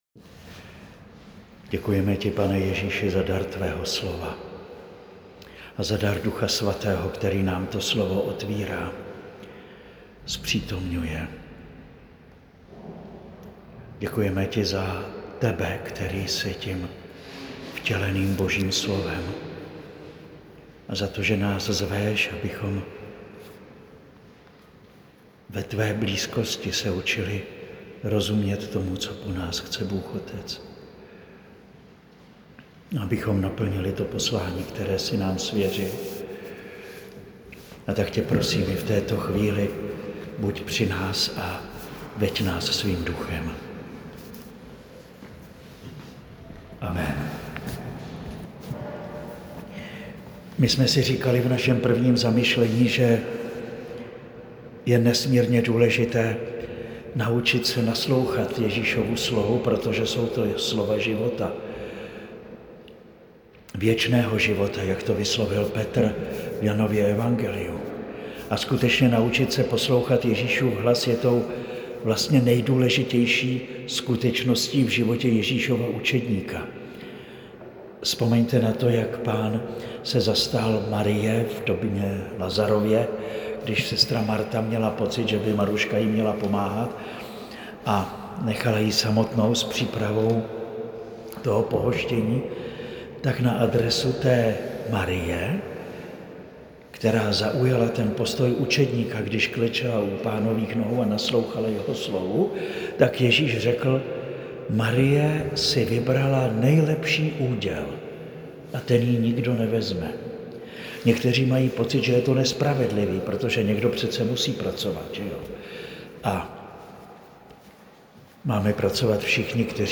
Nyní si můžete poslechnout druhou přednášku ze dvou.